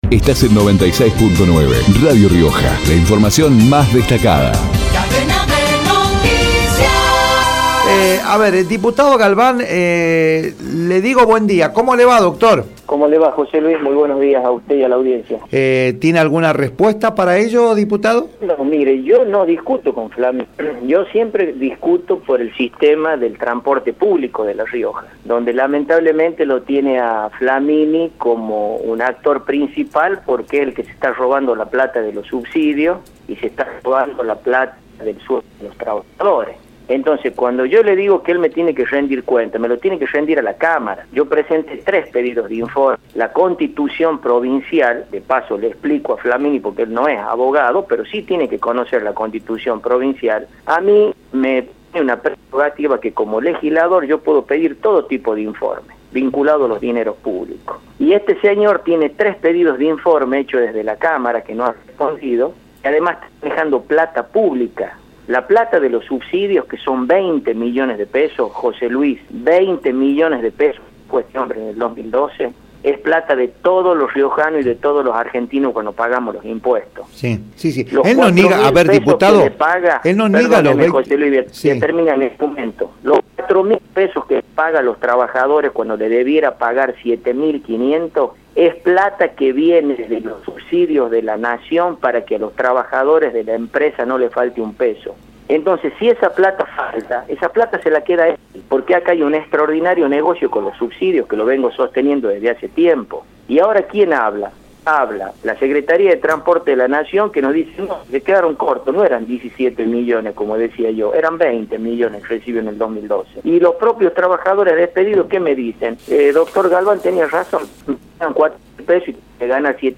Guillermo Galván, diputado radical, por Radio Rioja